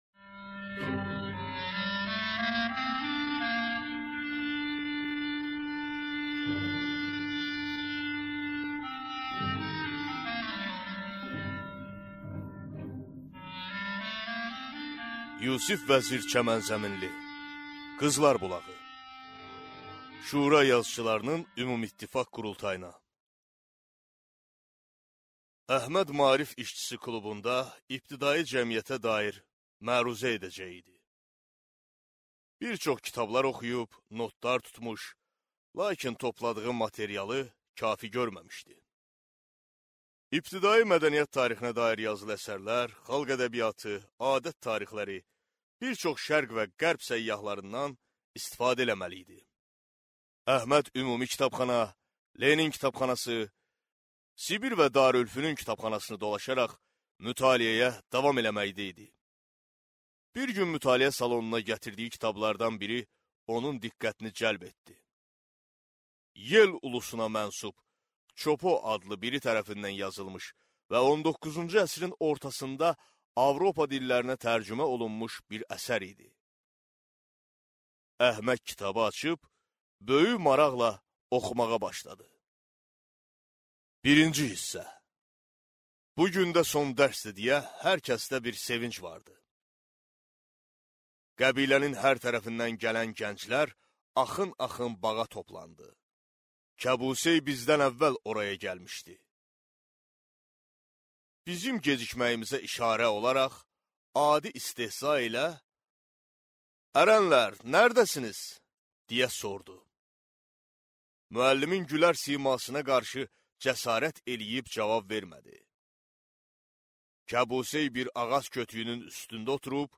Аудиокнига Qızlar bulağı | Библиотека аудиокниг